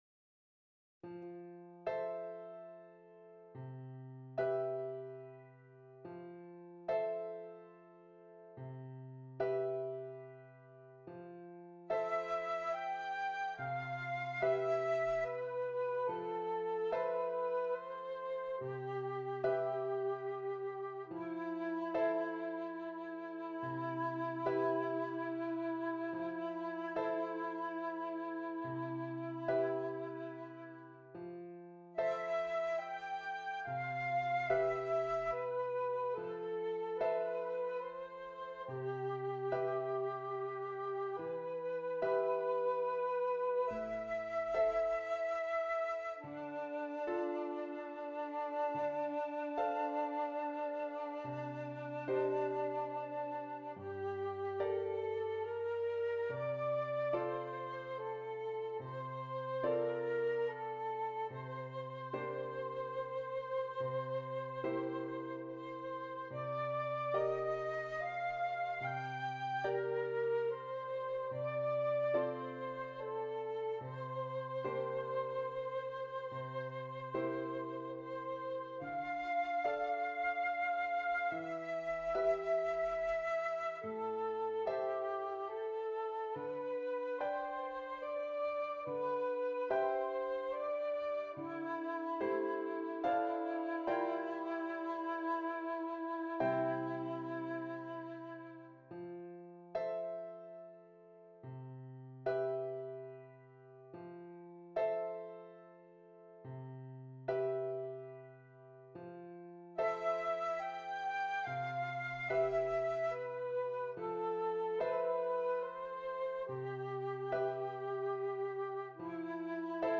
Flute and Keyboard
quiet minimal pieces